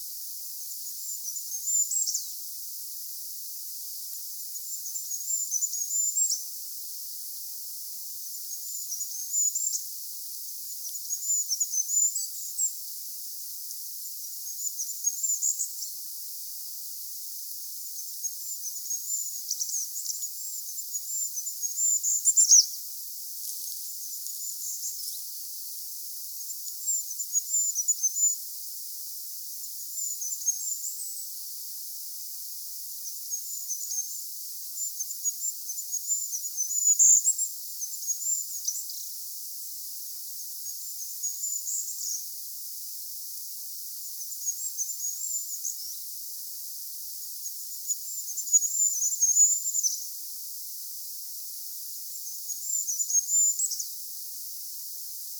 hippiäinen laulaa
hippiainen_laulaa.mp3